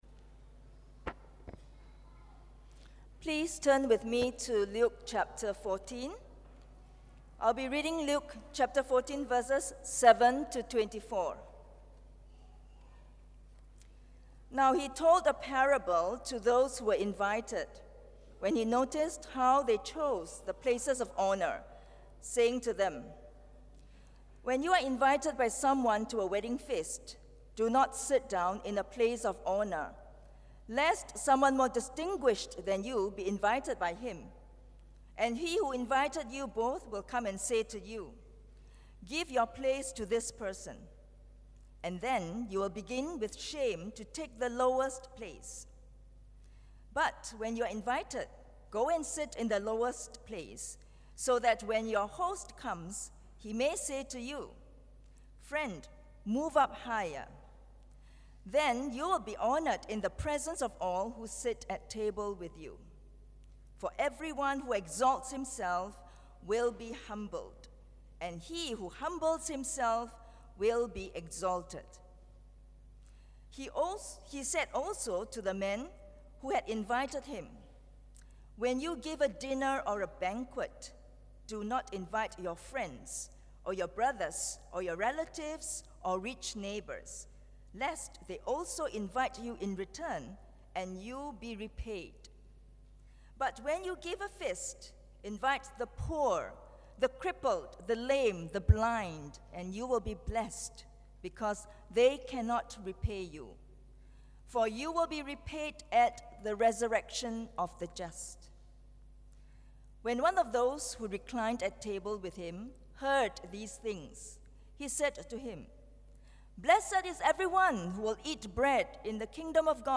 Don’t Miss Out On God’s Banquet (Luke 14:7-24) | Ang Mo Kio Presbyterian Church